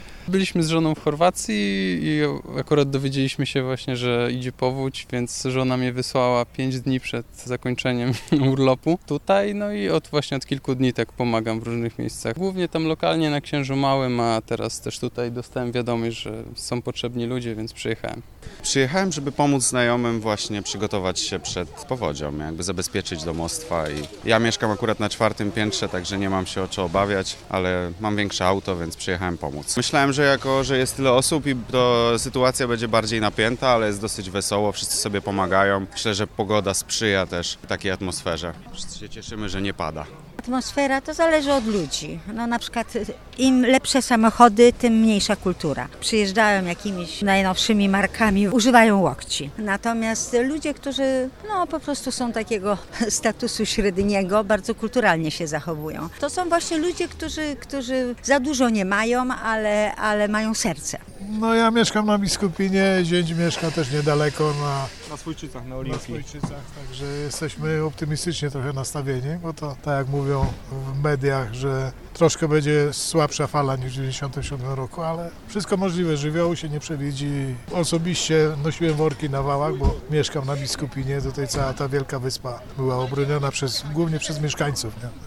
Przy Stadionie Olimpijskim cały czas jest ruch. Zapytaliśmy wrocławian o ich sytuację oraz atmosferę na miejscu.